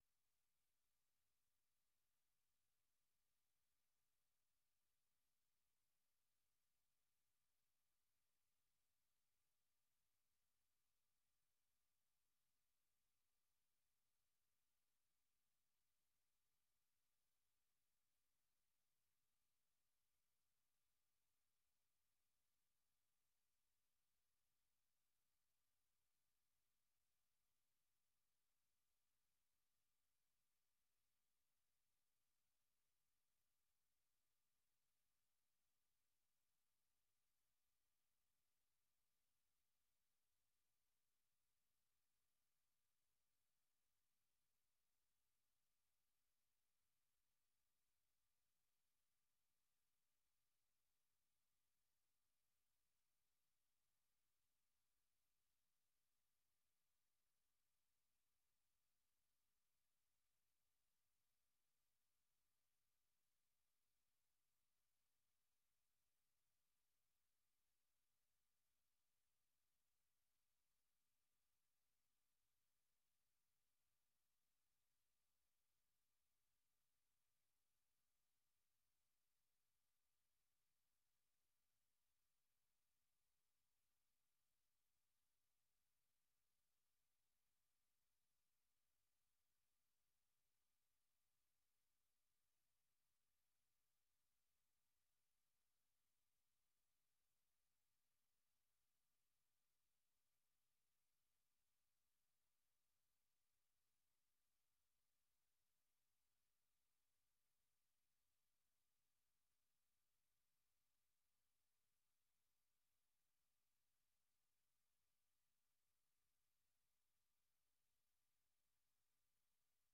Learning English uses a limited vocabulary and are read at a slower pace than VOA's other English broadcasts.